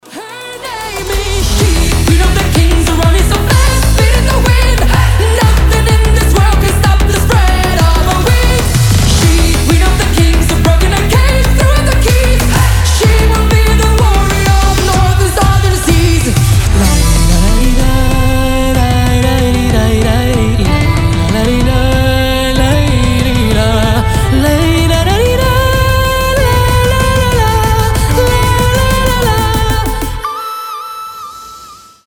поп
женские , сильный голос , melbourne bounce , энергичные
громкие